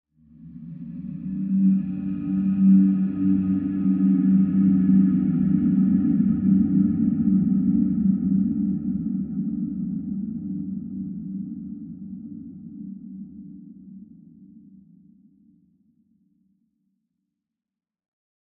Minecraft Version Minecraft Version latest Latest Release | Latest Snapshot latest / assets / minecraft / sounds / ambient / nether / soulsand_valley / mood1.ogg Compare With Compare With Latest Release | Latest Snapshot